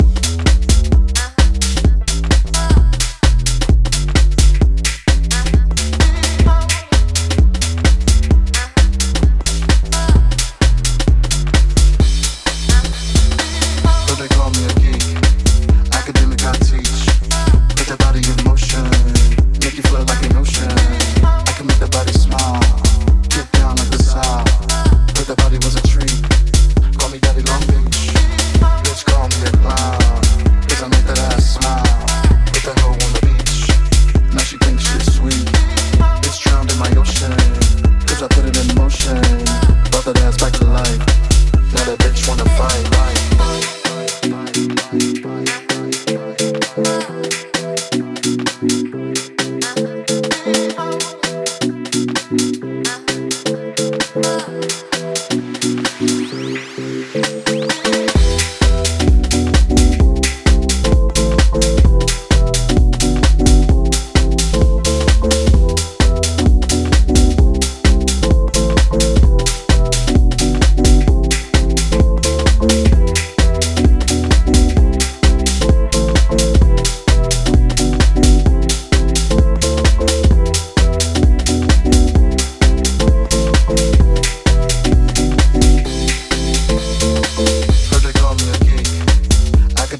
BPM130までピッチアップしながら、モダン・クラシカルかつ疾走感溢れる内容へと仕上げています！
Remix